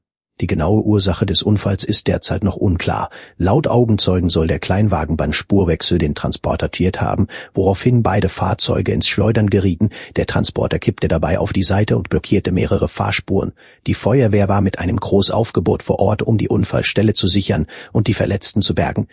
Here a unseen sample with a news article and a speaker from the training data: